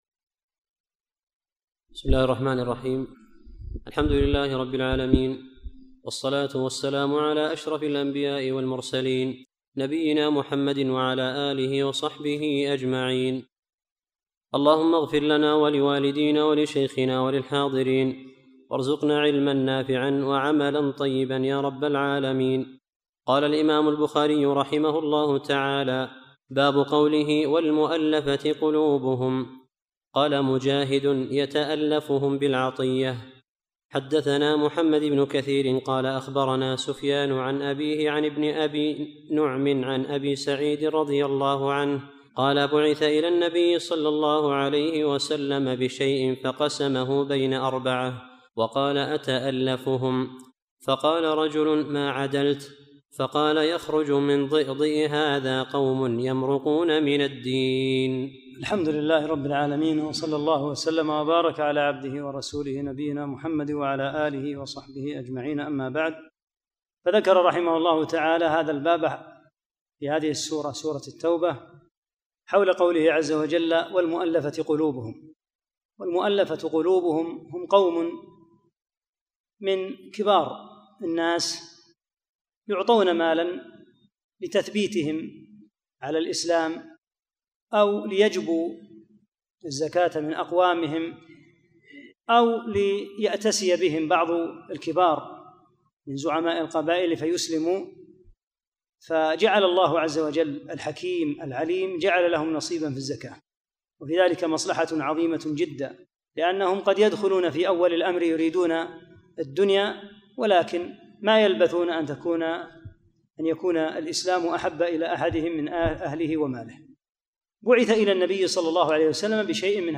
16 - الدرس السادس عشر